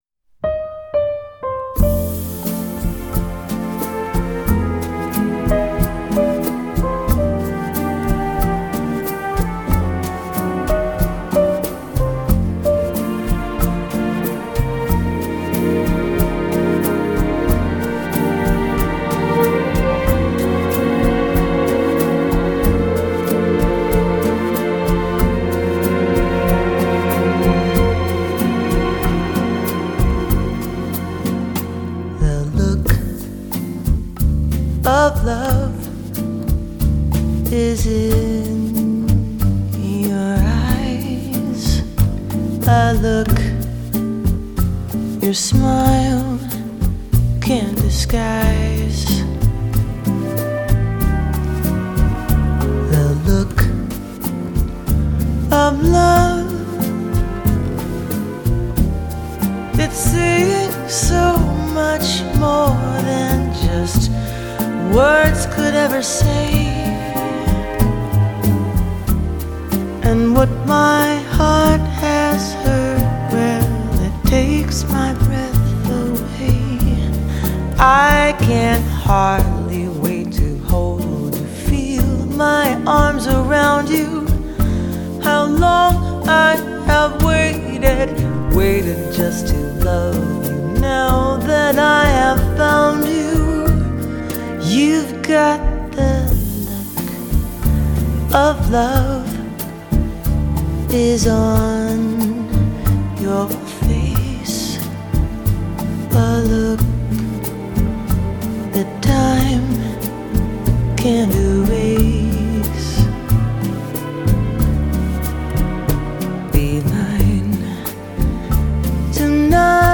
音樂類型：爵士樂